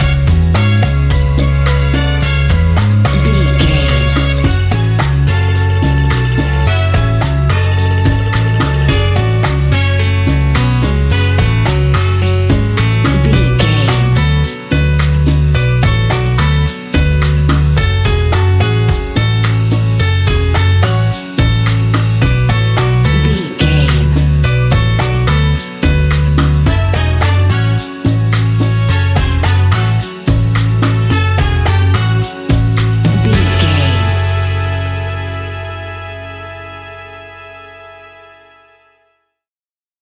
Aeolian/Minor
F#
calypso
steelpan
drums
bass
brass
guitar